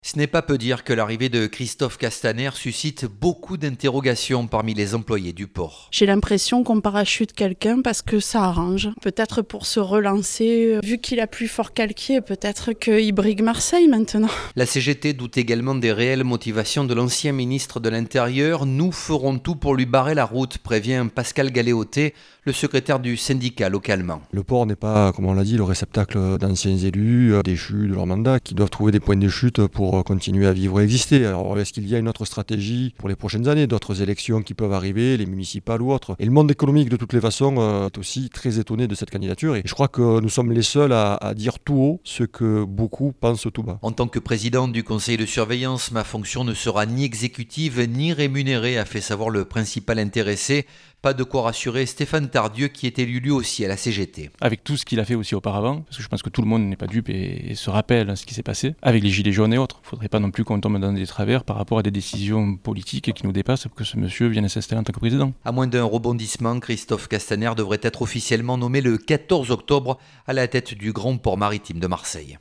Christophe Castaner devrait être le prochain président du port de Marseille. Un parachutage politique qui ne passe pas. Reportage.